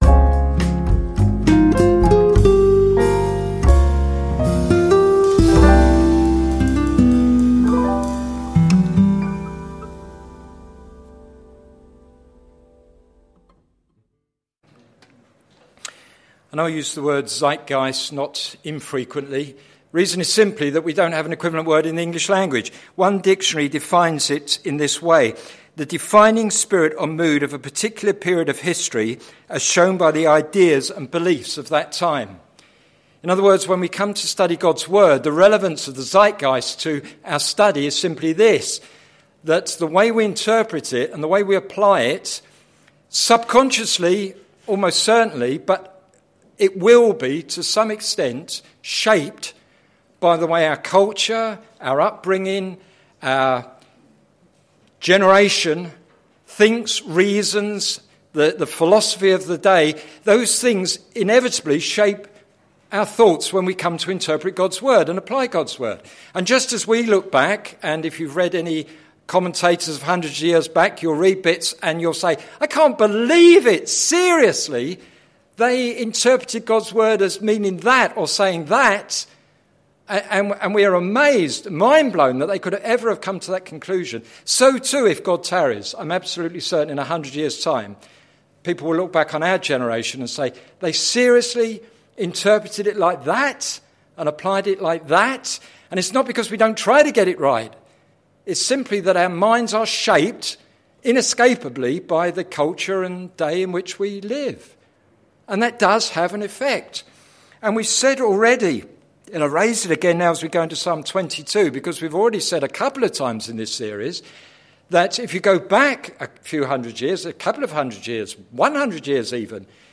Sermon Series - Songs to live and songs to sing - plfc (Pound Lane Free Church, Isleham, Cambridgeshire)